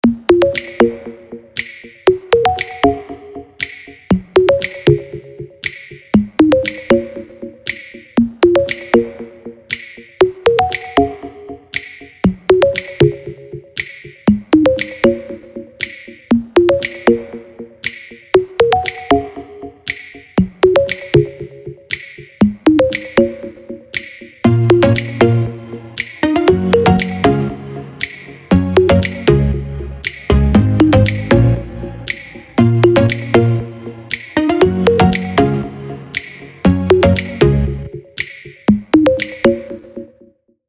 • Качество: 320, Stereo
Electronic
без слов
в электронной обработке.